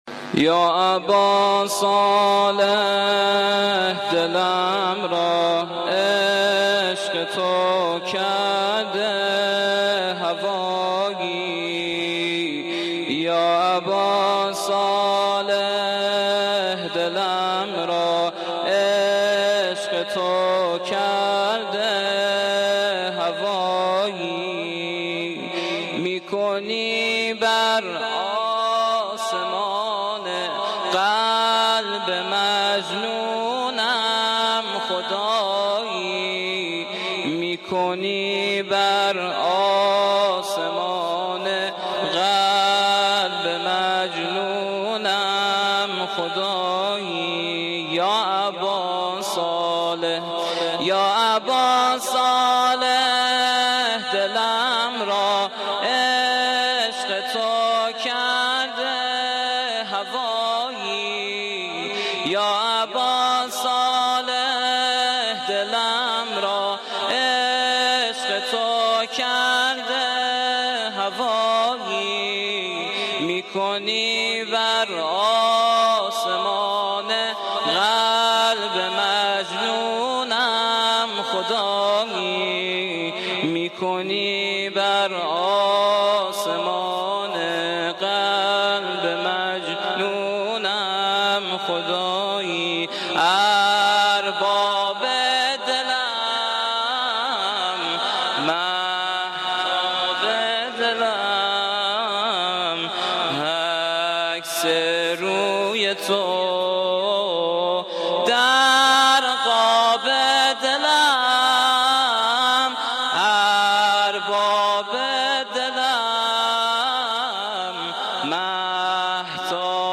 گزارش صوتی هشتمین کرسی تلاوت و تفسیر قرآن کریم - پایگاه اطلاع رسانی ضیافت نور